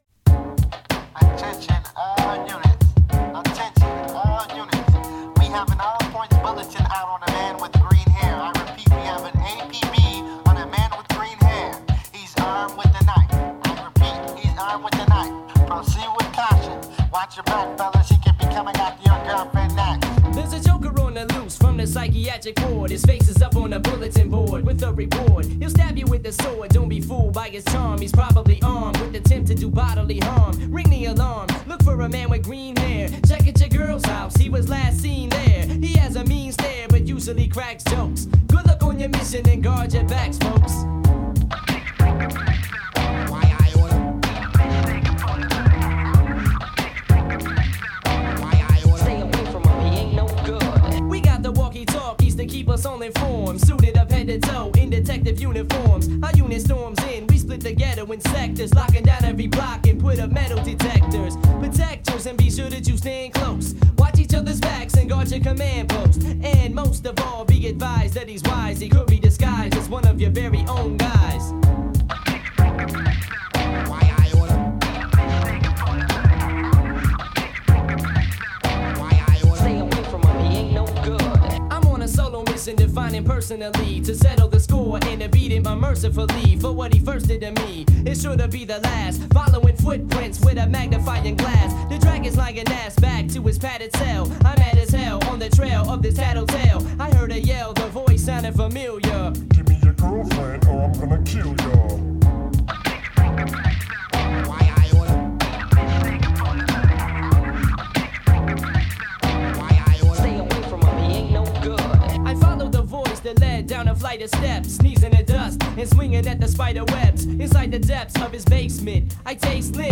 Рэп музыка